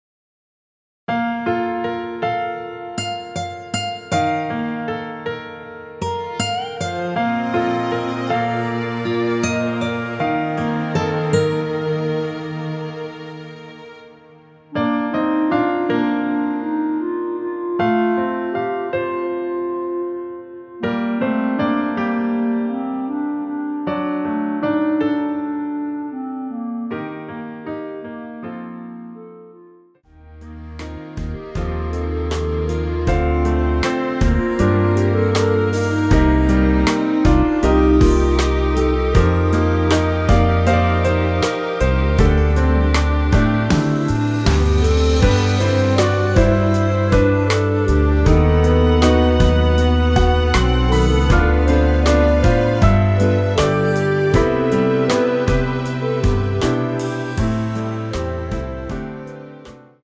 원키에서(-10)내린 멜로디 포함된 MR입니다.
Bb
앞부분30초, 뒷부분30초씩 편집해서 올려 드리고 있습니다.